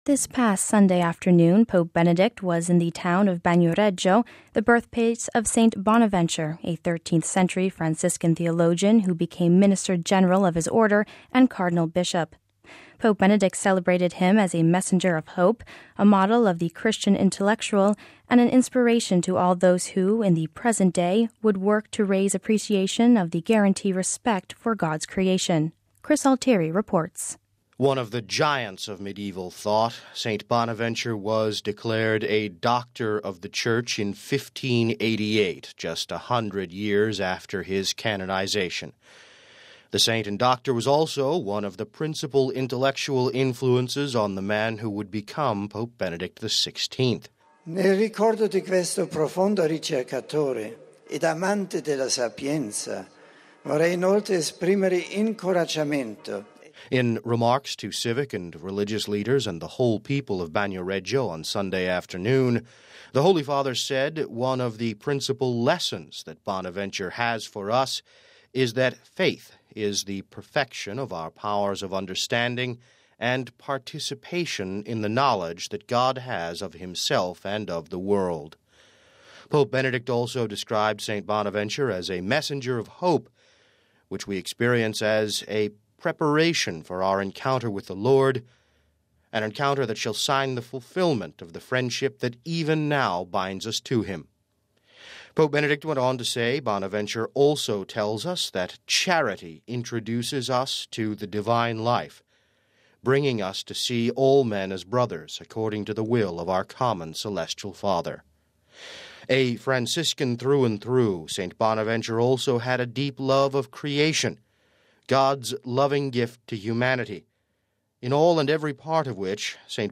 (7 Sept 09 - RV) This past Sunday afternoon, Pope Benedict was in the town of Bagnoregio, the birthplace of St. Bonaventure, a 13th century Franciscan theologian who became minister general of his order, and cardinal bishop.